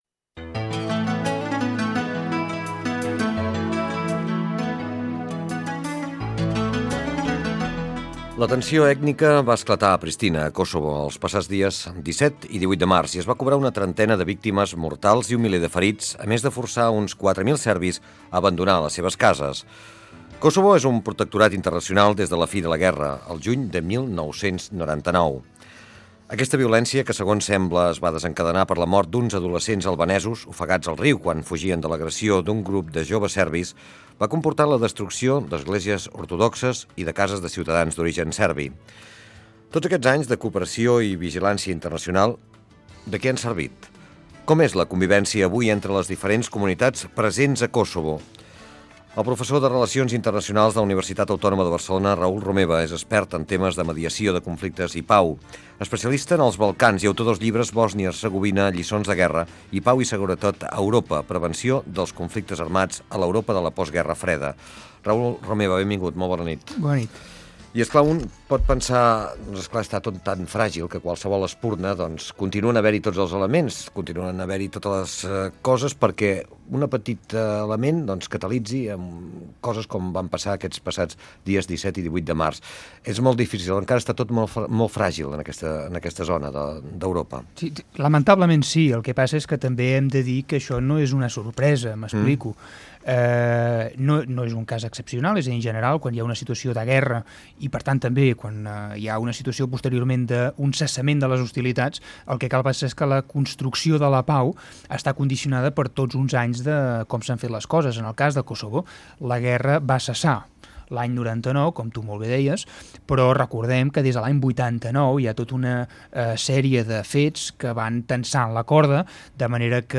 Violència a Kosovo. Fragment d'una entrevista a Raül Romeva, professor de relacions internacionals de la Universitat Autònoma de Barcelona